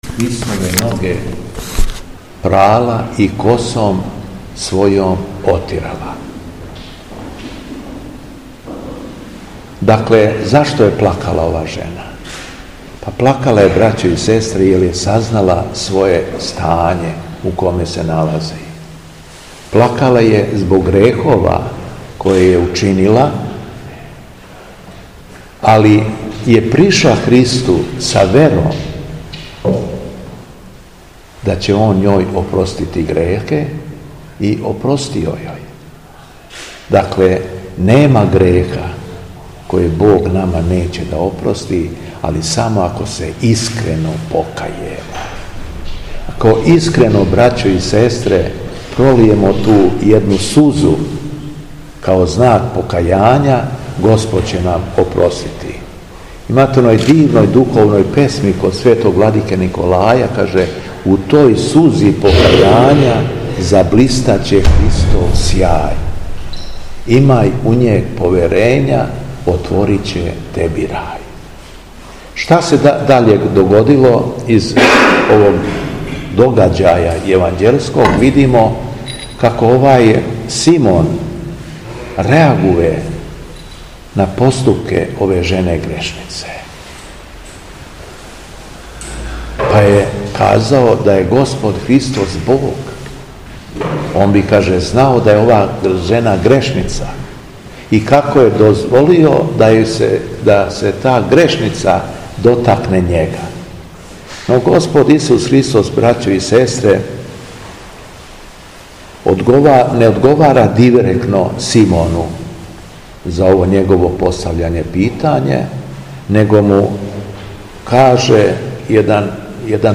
Беседа Његовог Преосвештенства Епископа шумадијског г. Јована